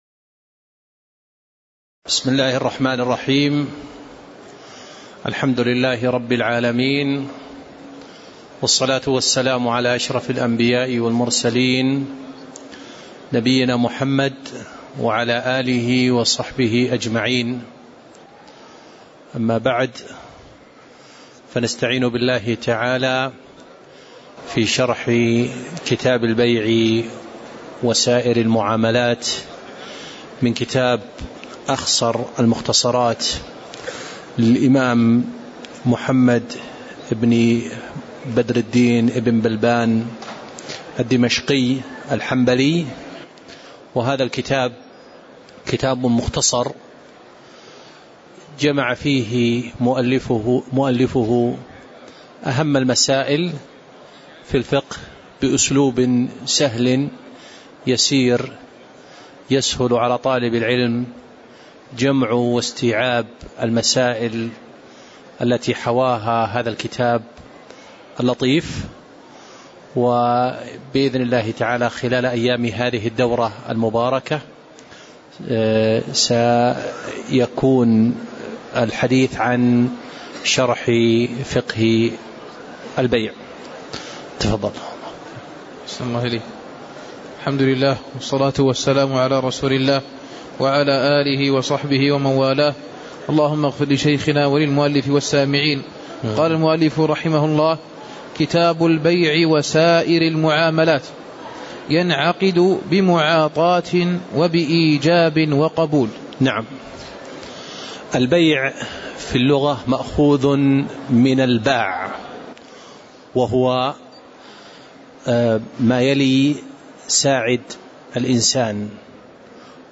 تاريخ النشر ٢٩ ربيع الأول ١٤٣٨ هـ المكان: المسجد النبوي الشيخ